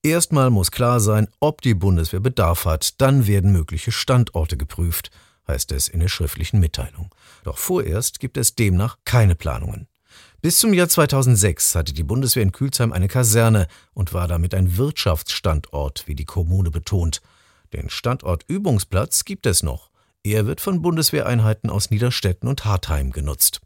Thomas Schreglmann, Bürgermeister von Külsheim